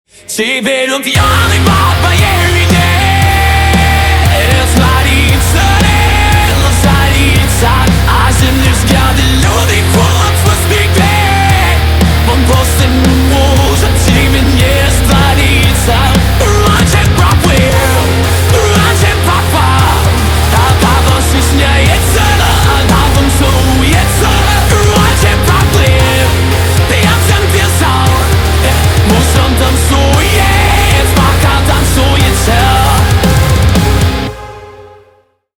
гитара
громкие
мощные
mash up
heavy Metal
Rock
хеви-метал